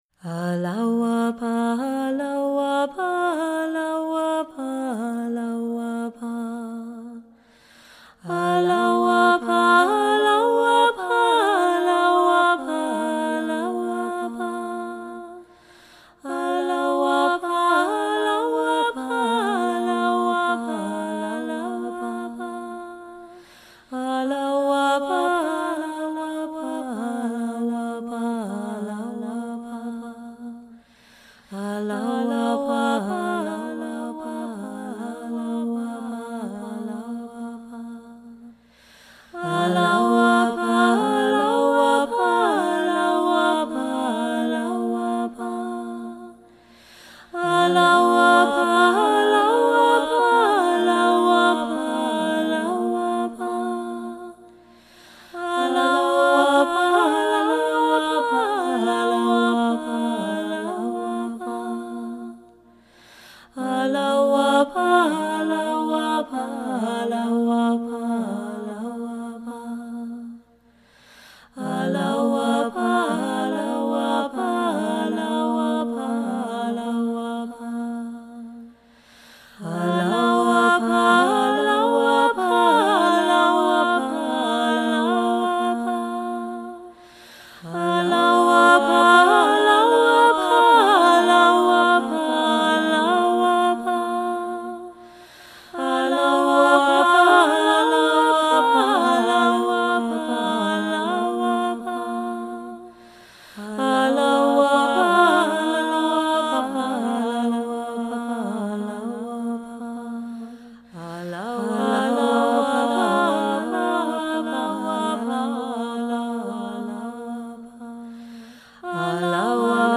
solo zikr
3-layer remix
there is no other processing or editing...